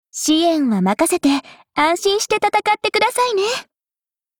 Cv-51901_battlewarcry.mp3